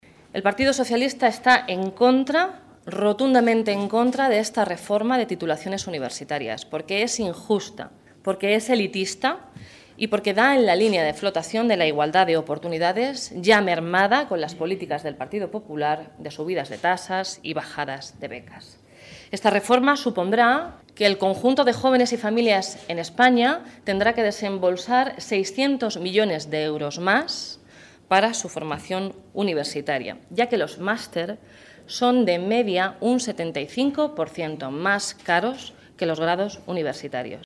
Fotografía de Blanca Fernández en rueda de prensa